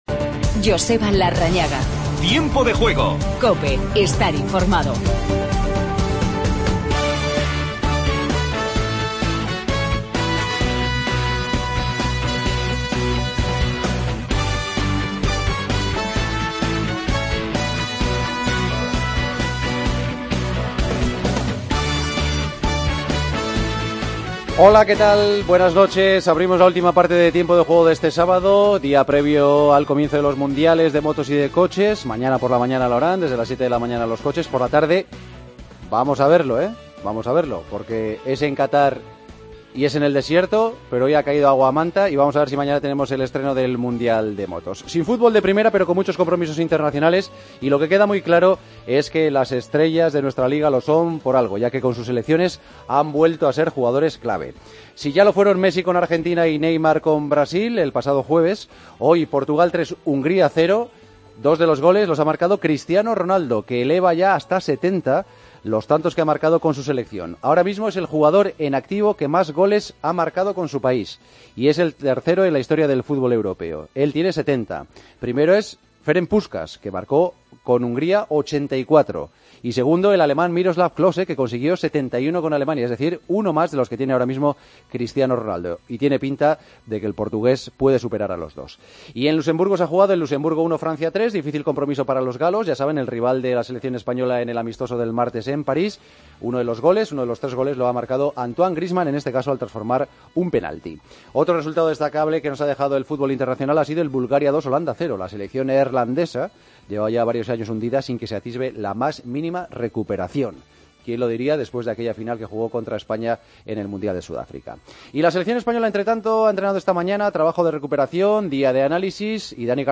Entrevista a Vicente del Bosque.